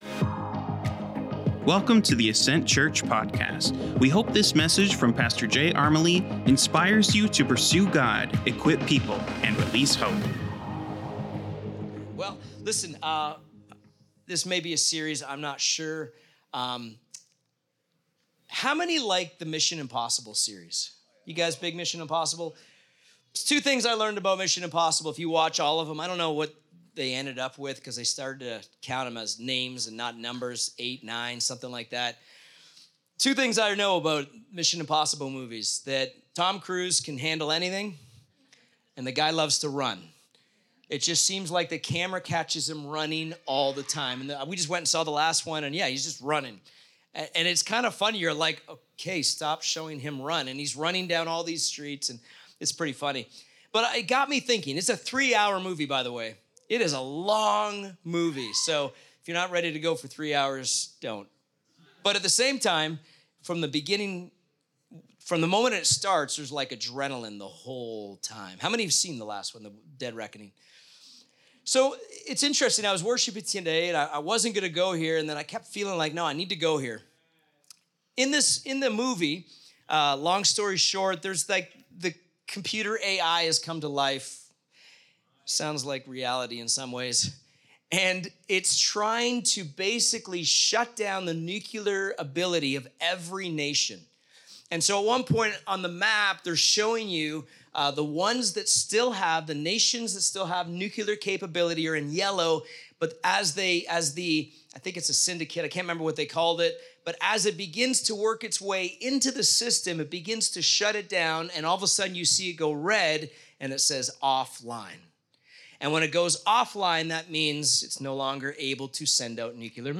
Sermons | Ascent Church